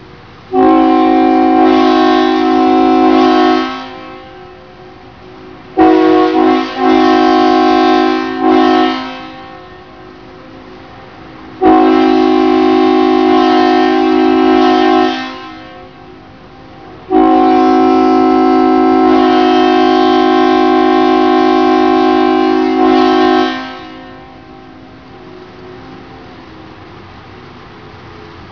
This Section For Non UK Horns
LK-Leslie-S3E-3a.wav